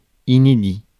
Ääntäminen
UK : IPA : /njuː/ US : IPA : /n(j)uː/ US : IPA : [nu]